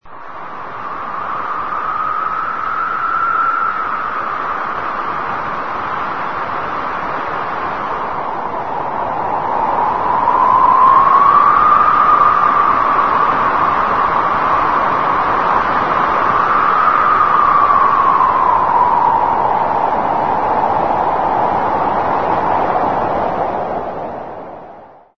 VIENTO7 VIENTO FUERTE
Ambient sound effects
Descargar EFECTO DE SONIDO DE AMBIENTE VIENTO7 VIENTO FUERTE - Tono móvil
Viento7_Viento_fuerte.mp3